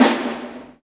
Index of /90_sSampleCDs/Roland LCDP03 Orchestral Perc/SNR_Orch Snares/SNR_Huge Snares